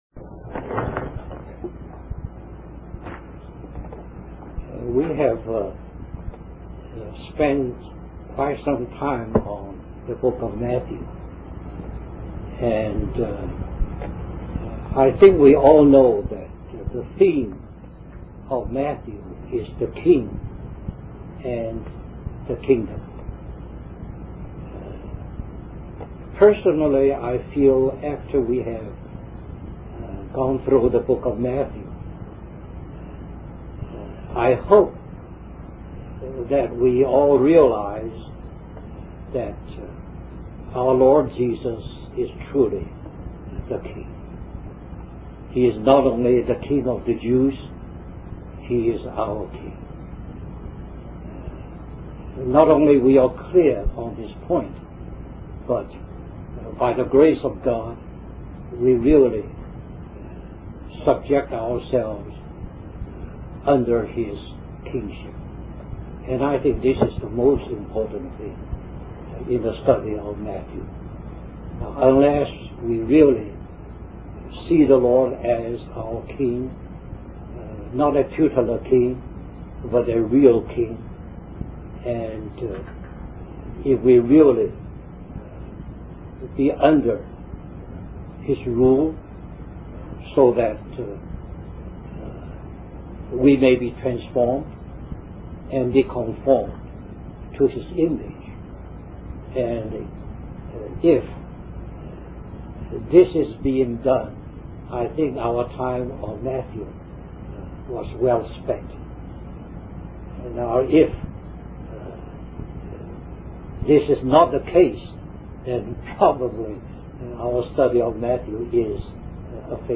US Stream or download mp3 Summary This message was given during a small group Bible study which had been studying the book of Matthew for several years.